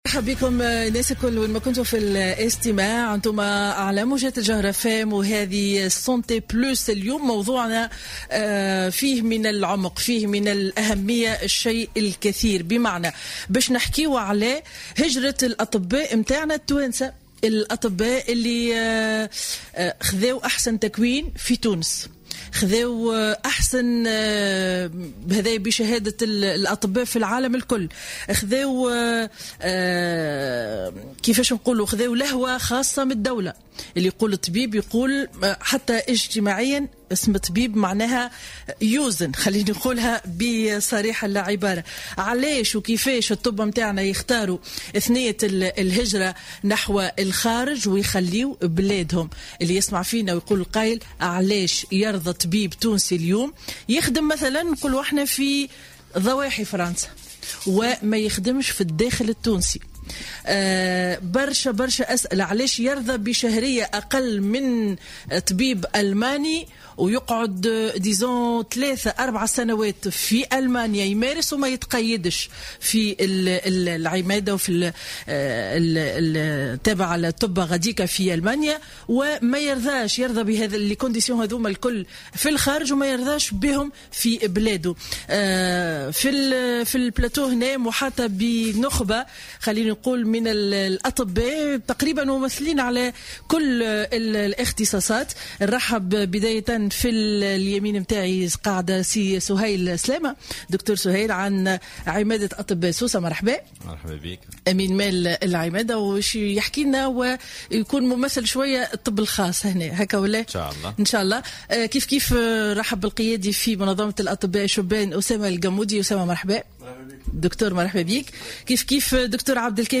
ضيف برنامج Santé+، اليوم الجمعة على الجوهرة أف أم